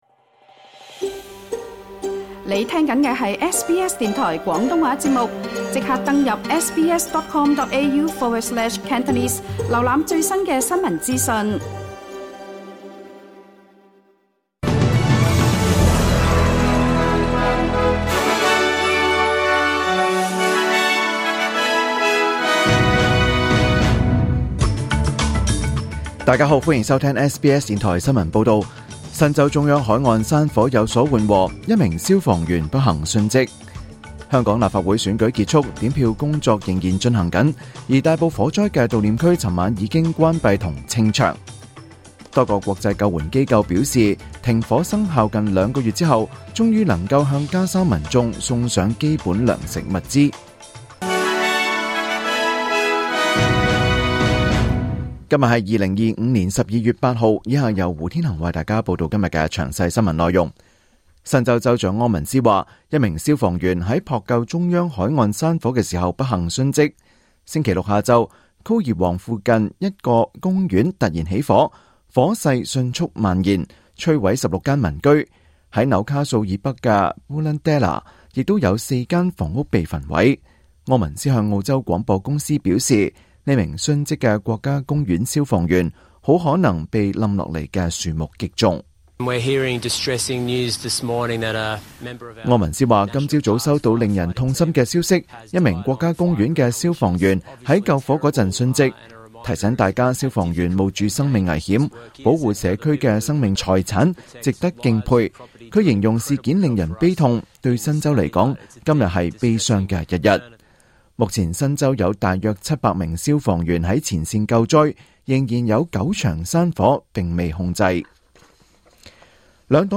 SBS廣東話新聞報道